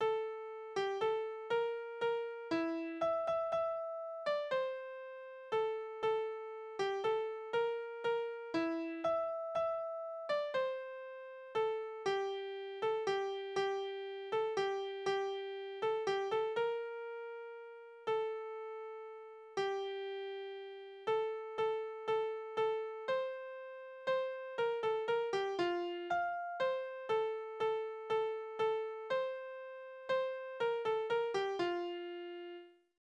Tanzverse:
Tonart: F-Dur
Taktart: 3/4
Tonumfang: kleine None
Besetzung: vokal
Anmerkung: Stückbezeichnung: Tanz